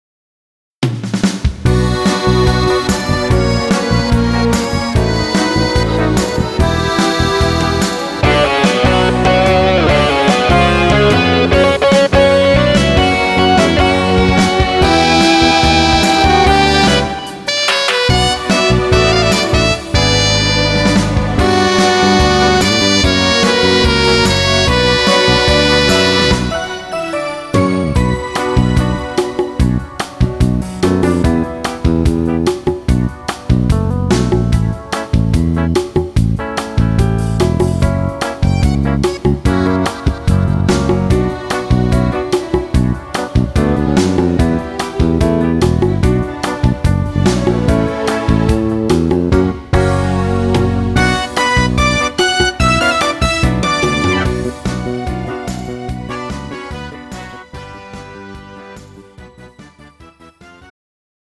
음정 -1키 3:31
장르 가요 구분 Pro MR